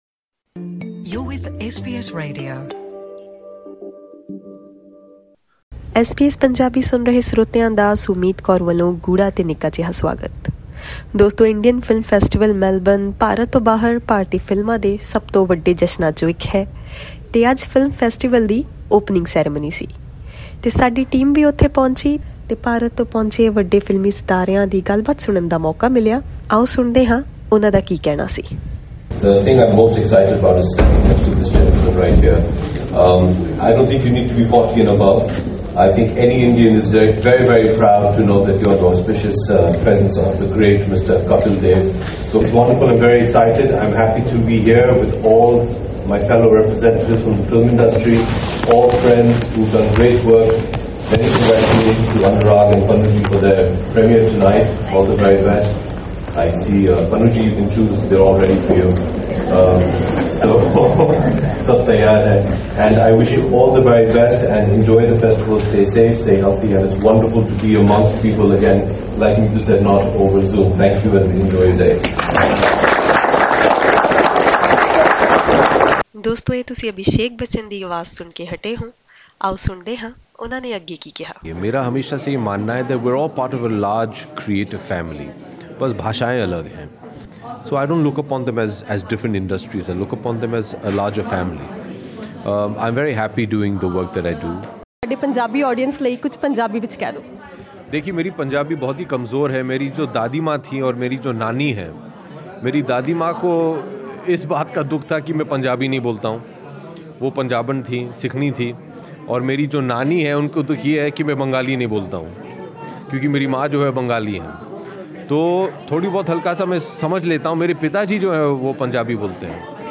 Listen to this podcast to hear what the stars have to say for their Punjabi audience in Australia.
Images from IFFM 2022 press conference.